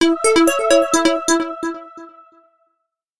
06734 angelic message ding-
angelic angels arp bless blessing choral ding electronic sound effect free sound royalty free Voices